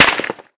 light_crack_03.ogg